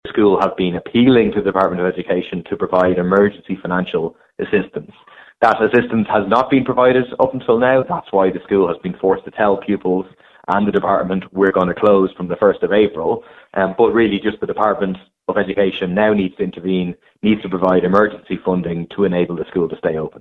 People Before Profit TD Paul Murphy says the government must urgently help: